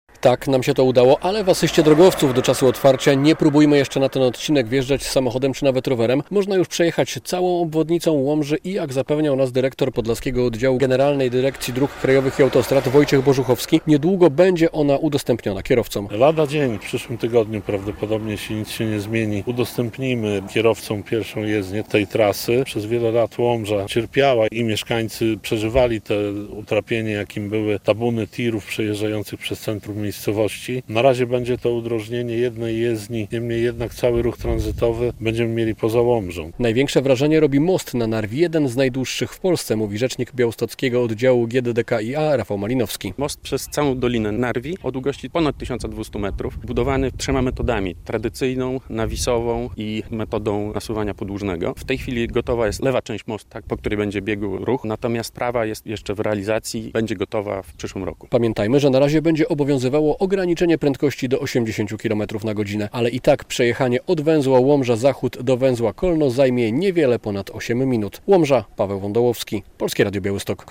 Ostatnie prace wykończeniowe na obwodnicy Łomży - relacja